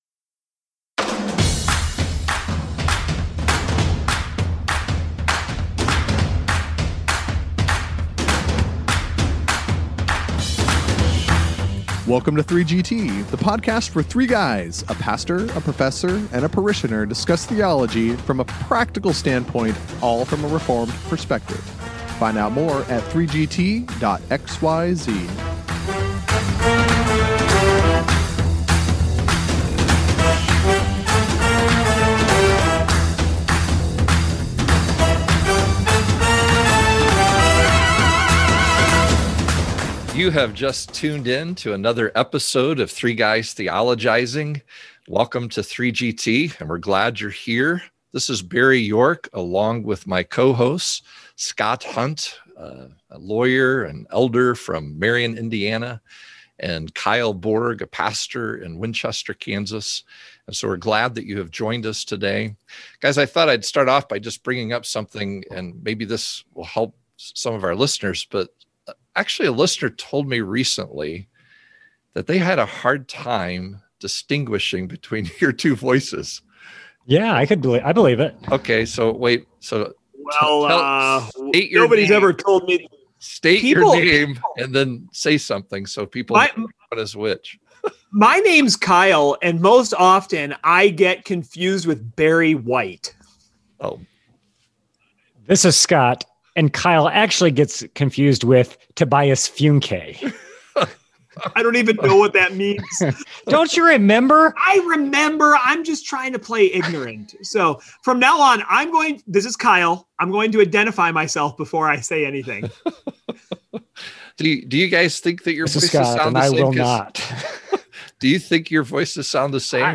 A listener thinks our pastor and parishioner sound so much alike you cannot tell them apart.